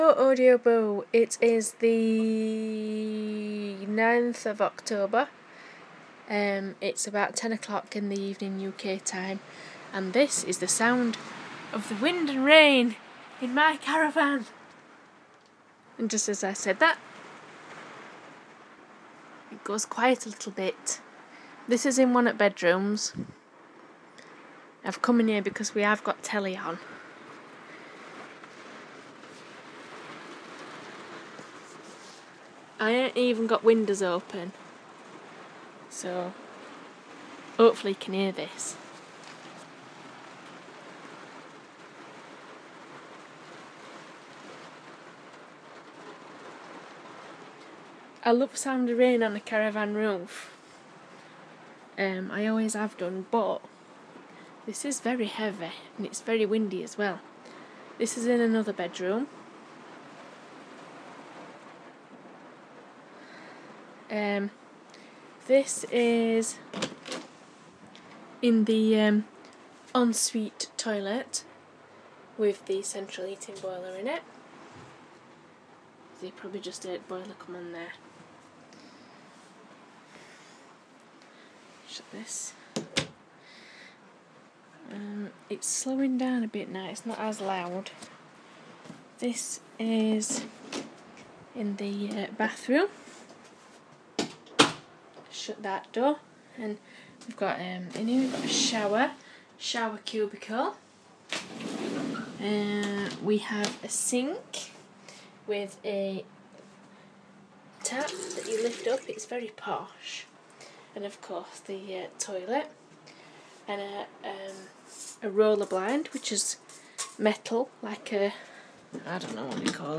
Rain and caravan